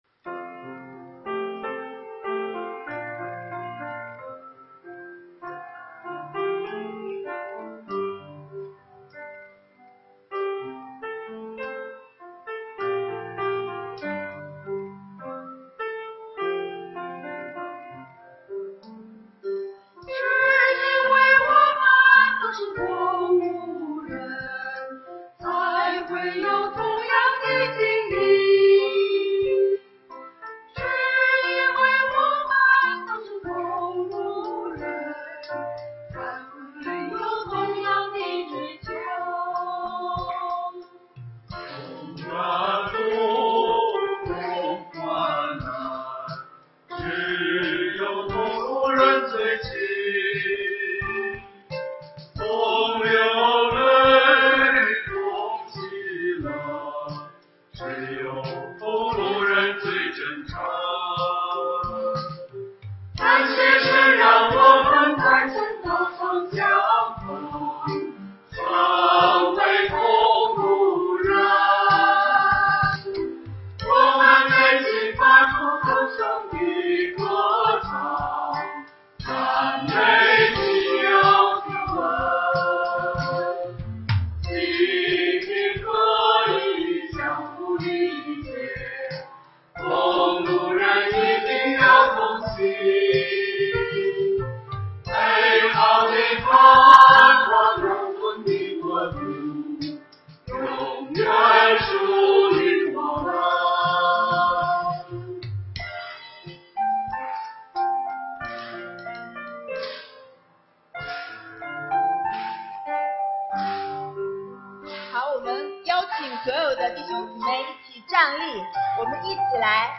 中秋赞美会：《同路人》 只因为我们都是同路人， 才会有同样的经历。
团契名称: 联合诗班 新闻分类: 诗班献诗 音频: 下载证道音频 (如果无法下载请右键点击链接选择"另存为") 视频: 下载此视频 (如果无法下载请右键点击链接选择"另存为")